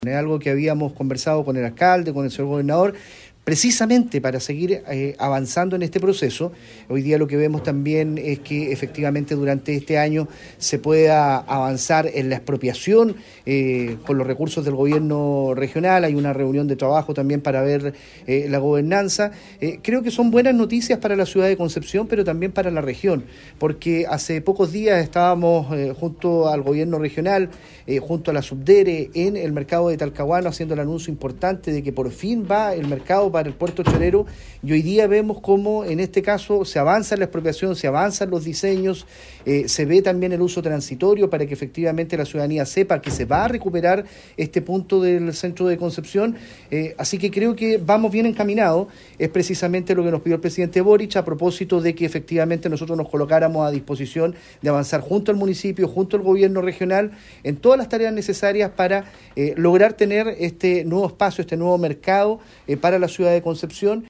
El delegado presidencial, Eduardo Pacheco, afirmó que “es una muy buena noticia que haya coincidencia entre todos los actores de la institucionalidad pública de que ese espacio de manera transitoria tiene que ser recuperado para poder desarrollar actividades y que termine de ser este punto negro que hay en el Centro de Concepción”.
MercadoCCP-1-Delegado.mp3